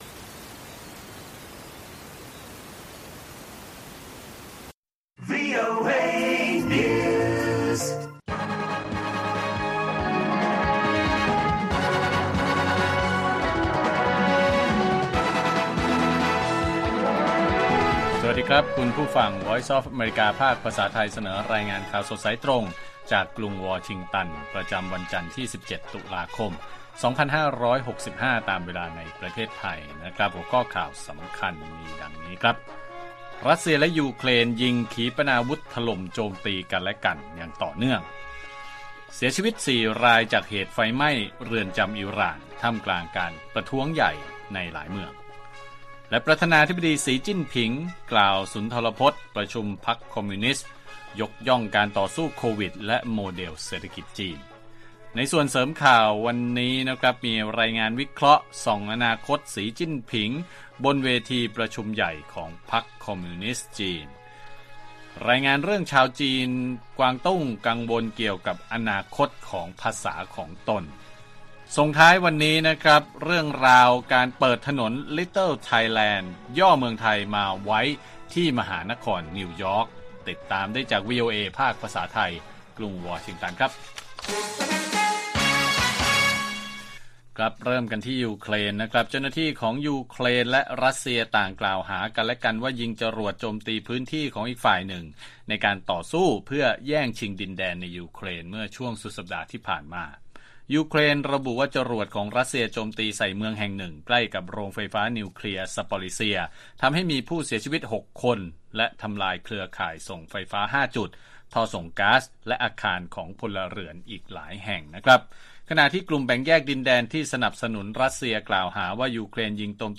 ข่าวสดสายตรงจากวีโอเอ ภาคภาษาไทย 8:30–9:00 น. วันจันทร์ ที่ 17 ต.ค. 2565